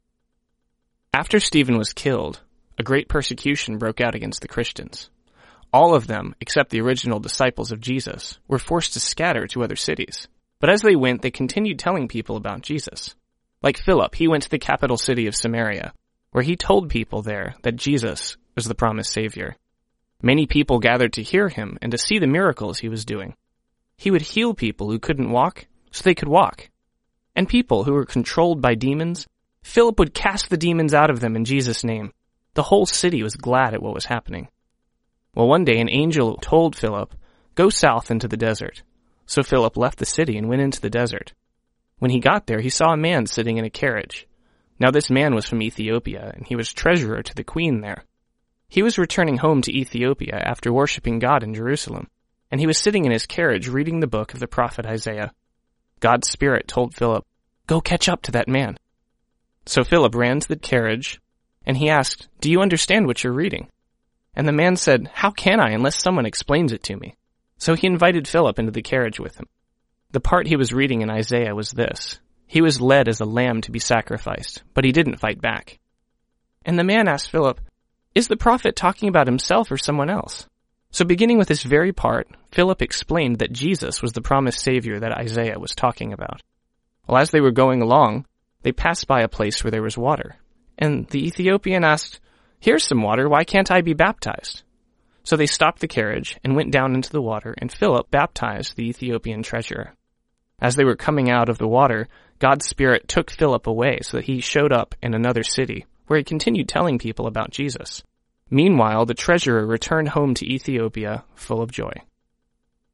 Deepen your intimacy with God by listening to an oral Bible story each day.